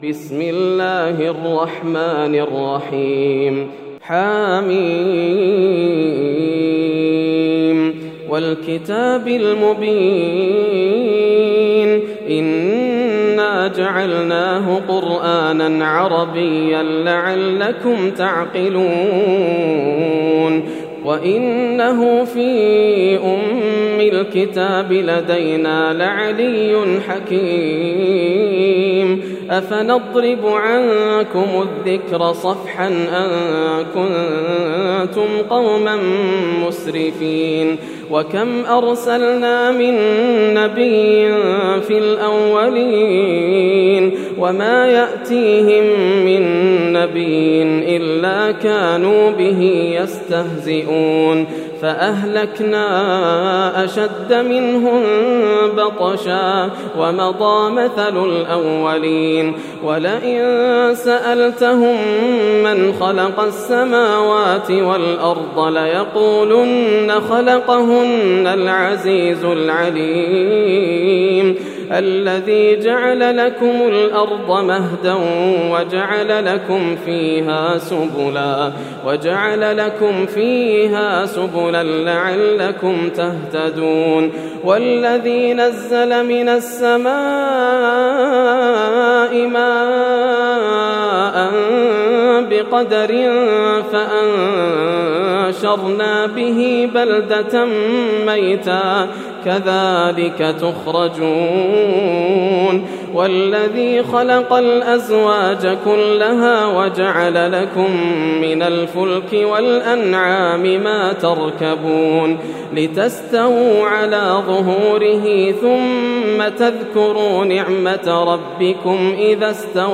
سورة الزخرف > السور المكتملة > رمضان 1431هـ > التراويح - تلاوات ياسر الدوسري